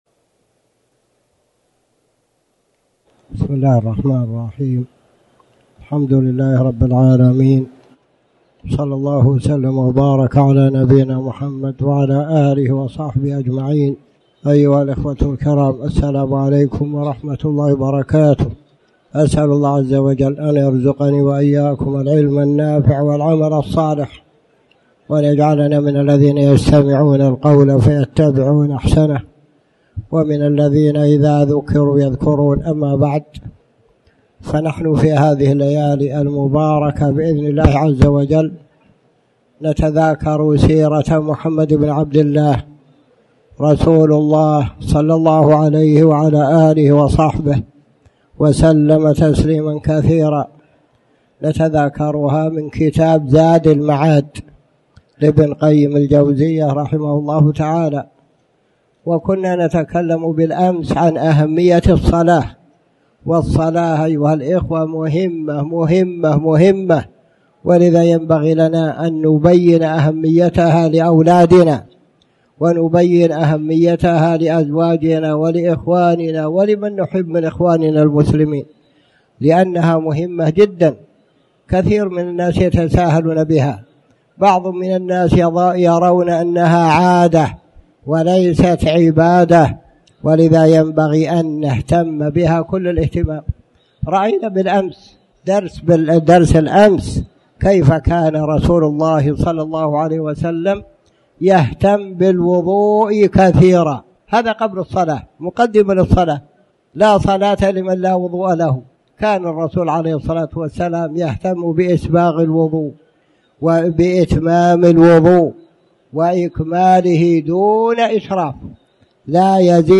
تاريخ النشر ١٧ رجب ١٤٣٩ هـ المكان: المسجد الحرام الشيخ